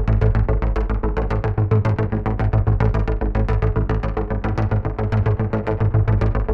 Index of /musicradar/dystopian-drone-samples/Droney Arps/110bpm
DD_DroneyArp3_110-A.wav